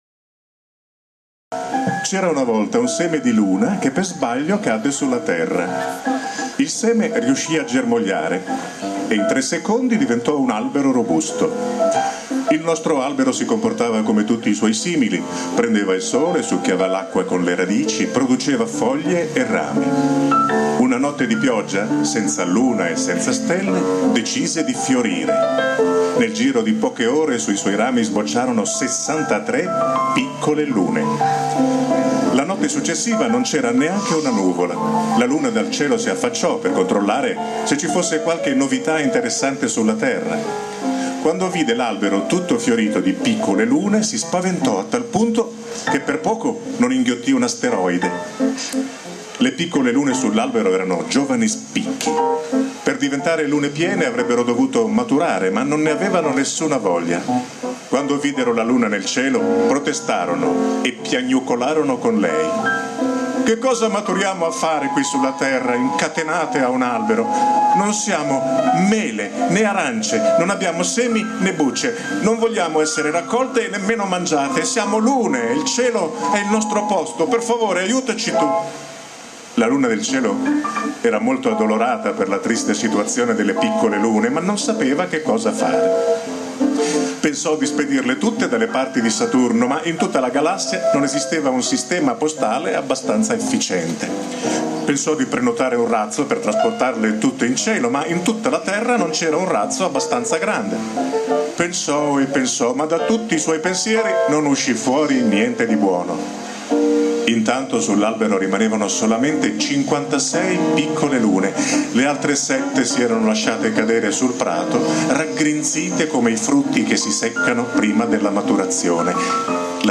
L'audioracconto in formato MP3 - parole di Roberto Piumini, musica di Stefano Bollani